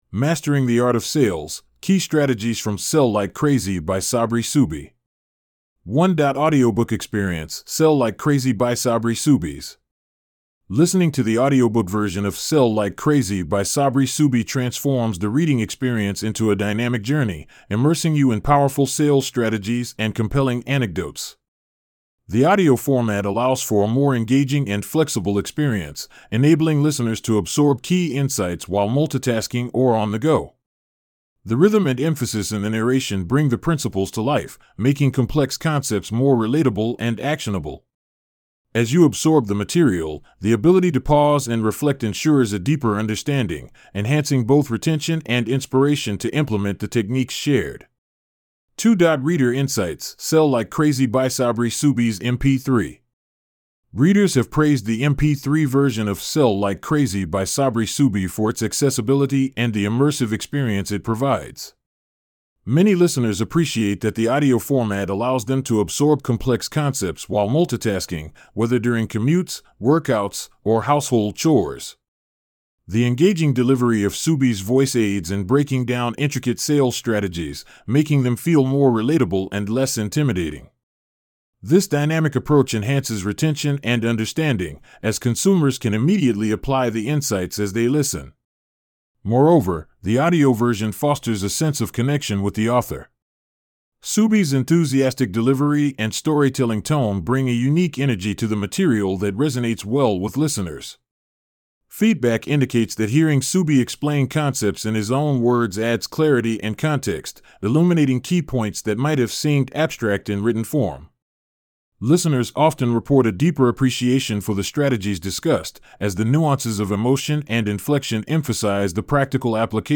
Resúmenes de lectura rápida